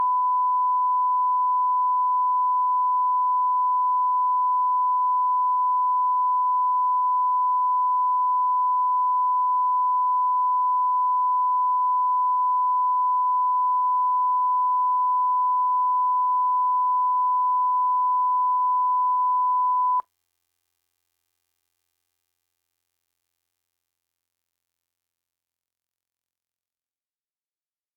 It sounds a bit like a noisy guitar amp.
This is just the “other” from the tone at the start of the source file.
The noise “rings” after the tone has stopped and is very clearly audible to me - even on this mp3 - with my monitors at standard mix level (calibrated for TV mixing).